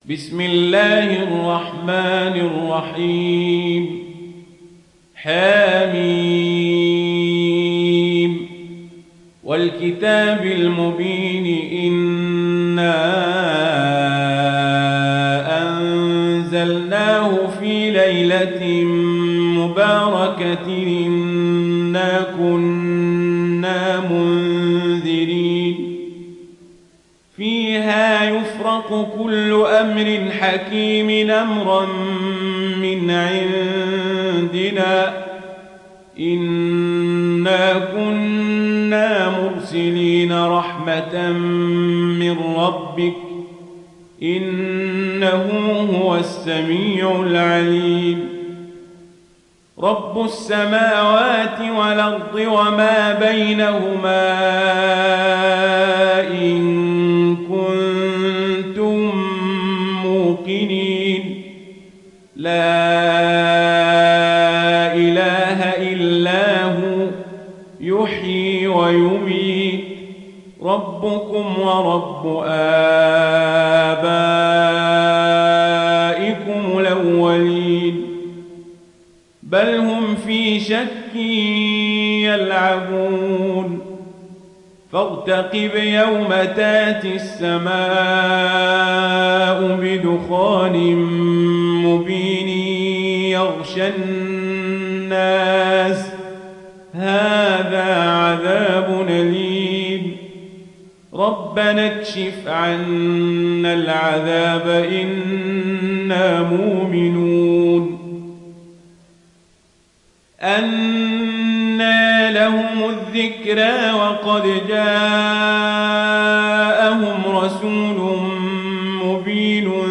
تحميل سورة الدخان mp3 بصوت عمر القزابري برواية ورش عن نافع, تحميل استماع القرآن الكريم على الجوال mp3 كاملا بروابط مباشرة وسريعة